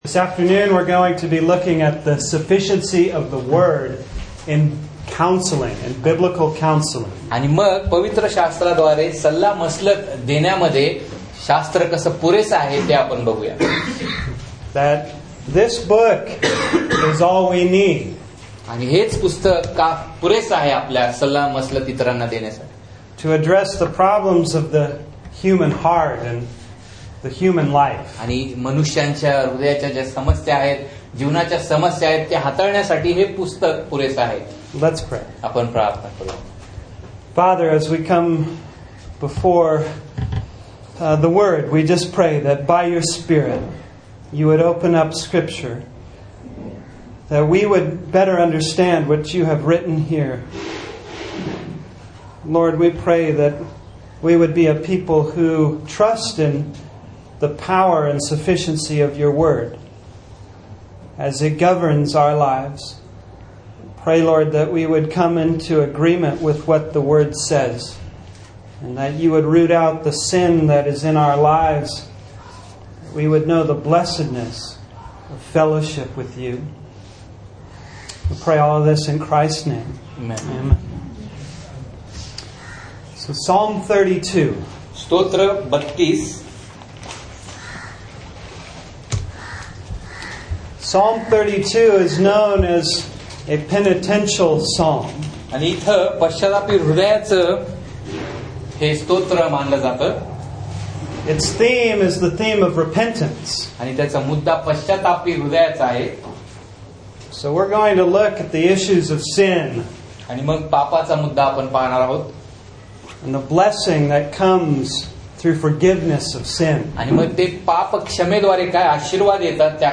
Passage: Titus 2:7-10 Service Type: Sunday Morning Titus